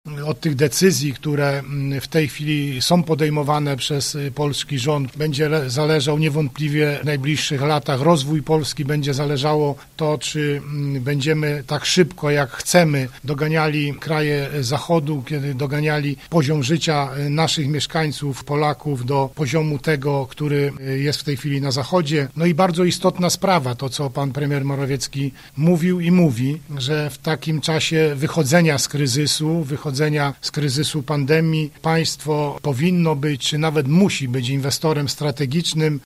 Mówi wojewoda Władysław Dajczak: